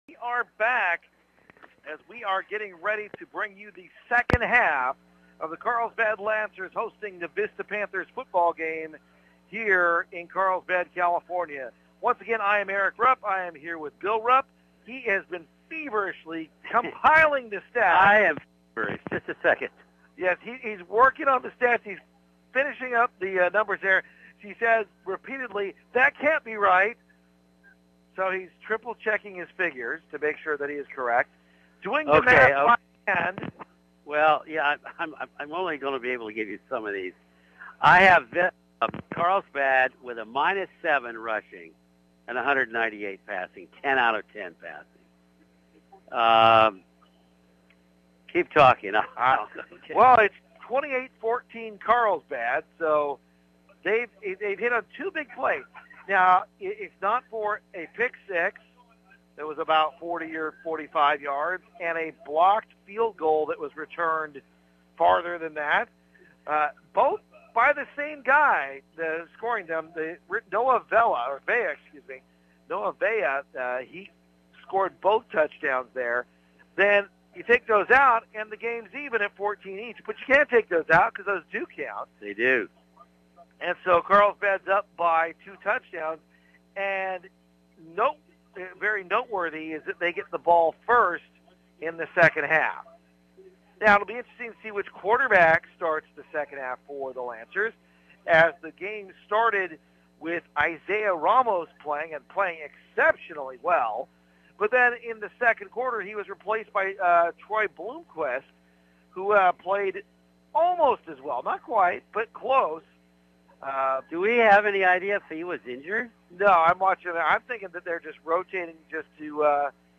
archived local high school football games in North San Diego County